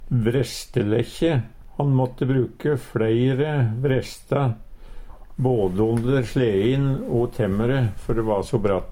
vrestelekkje Lekkje til å ha rundt tømmerstokkane slik at det bremsa mot underlaget Eintal ubunde Eintal bunde Fleirtal ubunde Fleirtal bunde lekkje lekkja lekkju lekkjun Eksempel på bruk Han måtte bruke fleire vrestelekkju før de va so bratt. Høyr på uttala Ordklasse: Substantiv hokjønn Kategori: Reiskap og arbeidsutstyr Attende til søk